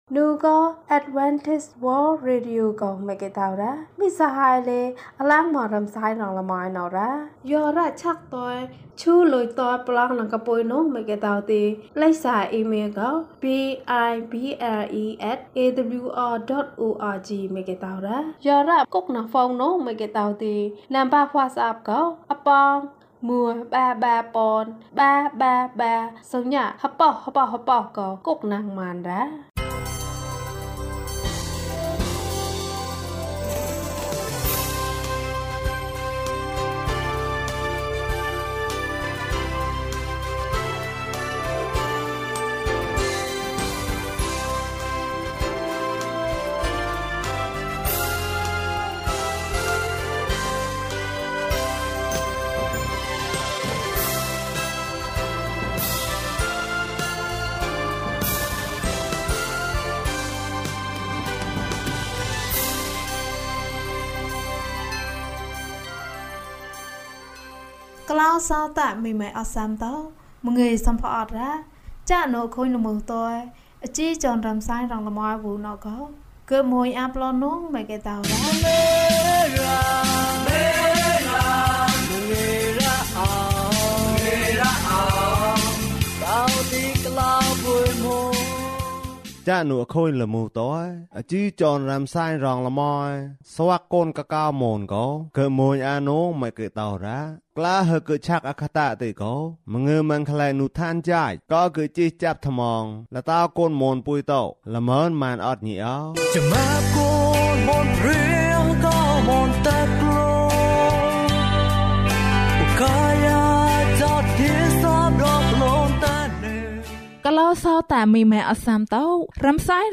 ကျန်းမာရေးအတွက်။ ကျန်းမာခြင်းအကြောင်းအရာ။ ဓမ္မသီချင်း။ တရားဒေသနာ။